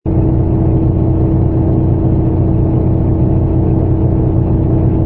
engine_oe_h_fighter_loop.wav